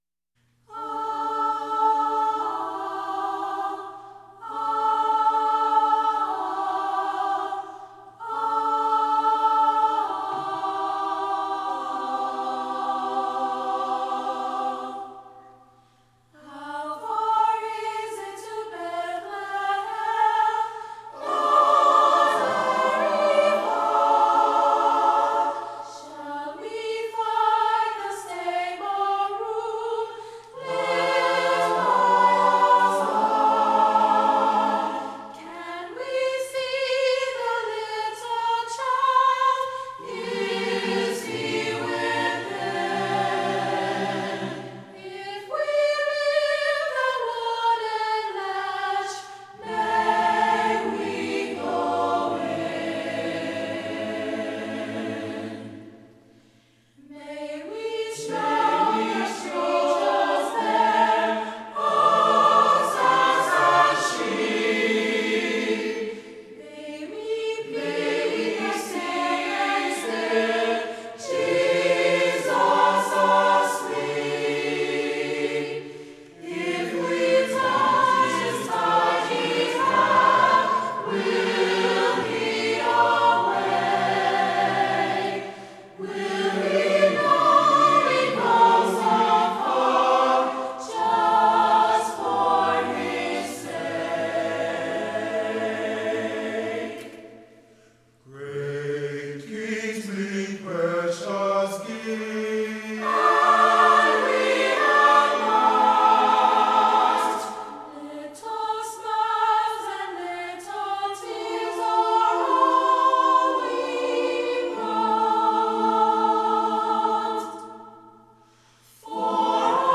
music, choral, choir, performance, christmas, holiday